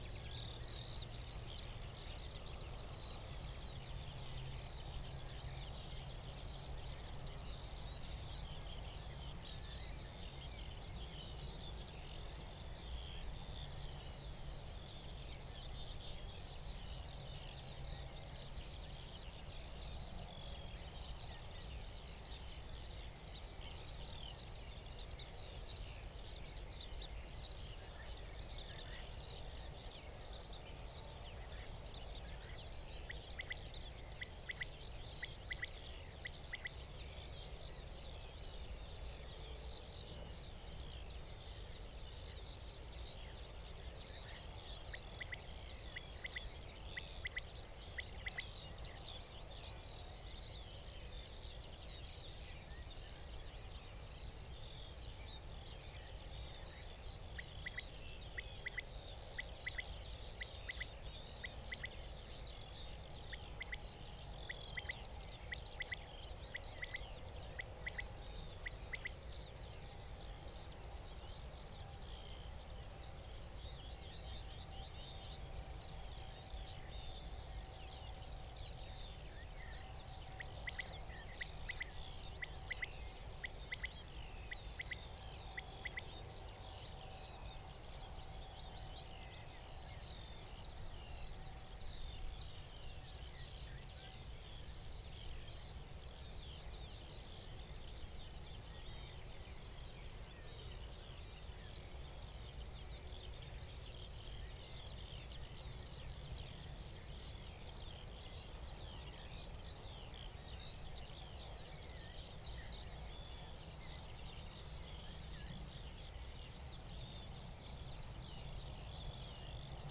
Corvus corone
Coturnix coturnix
Turdus merula
Sylvia communis
Pica pica
Alauda arvensis